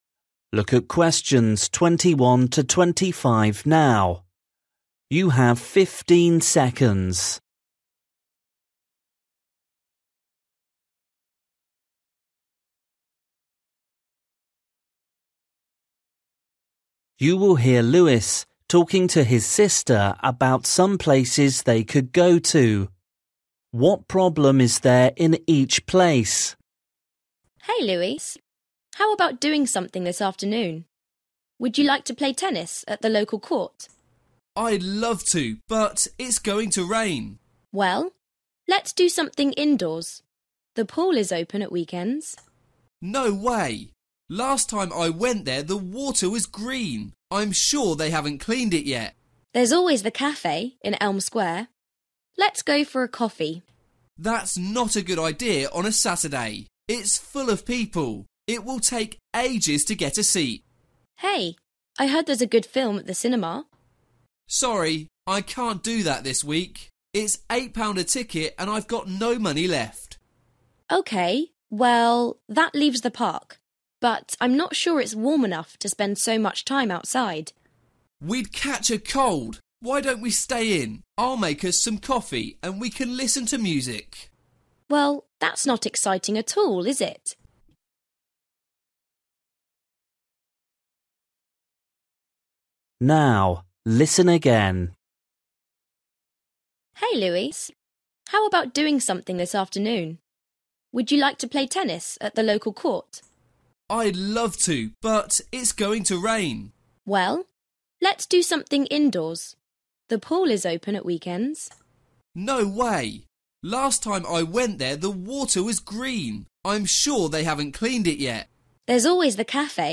You will hear Luis talking to his sister about some places they could go to.